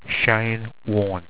Pronounced
SHAYN WORN